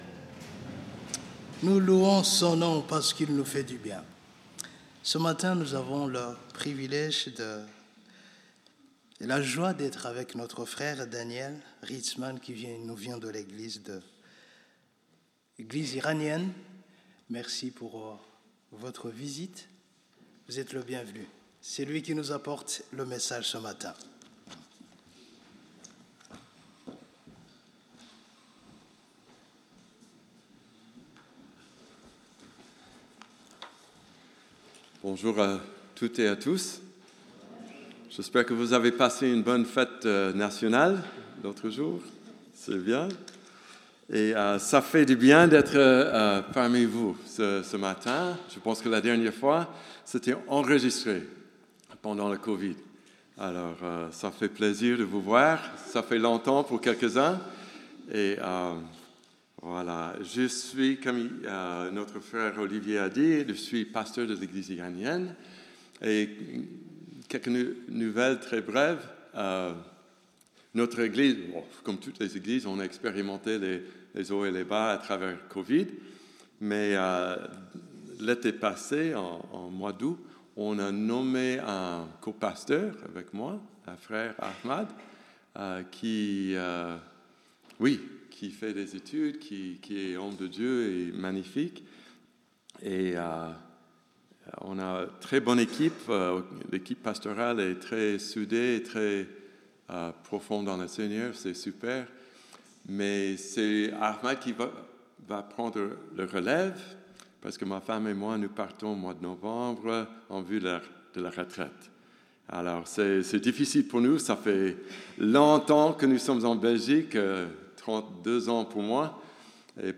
Prédication sur le psaume 1 - EPE Bruxelles